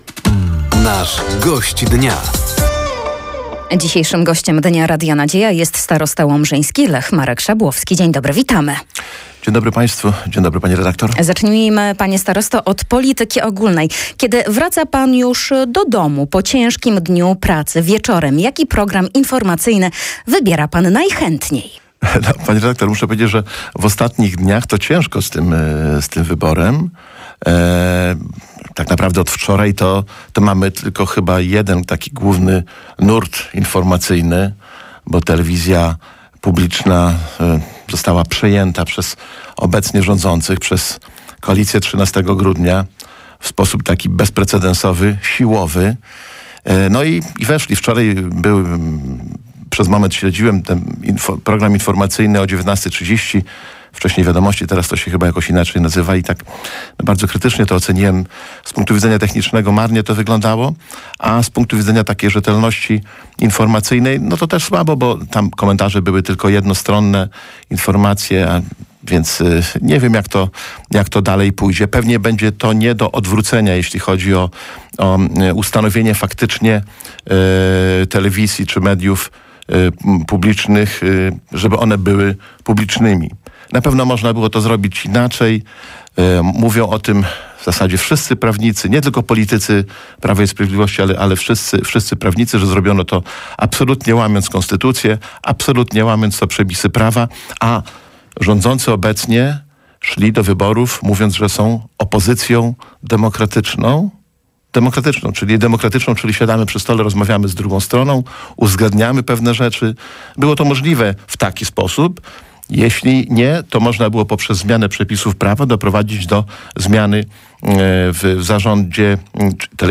Gościem Dnia Radia Nadzieja był starosta powiatu łomżyńskiego, Lech Szabłowski. Tematem rozmowy była obecna sytuacja telewizji publicznej, założenia powiatu łomżyńskiego na 2024 rok, a także nadchodzące wybory samorządowe.